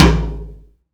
TOM LW FLO4W.wav